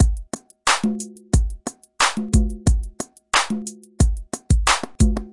嘻哈节拍 " 嘻哈节拍Loop5
描述：嘻哈循环
声道立体声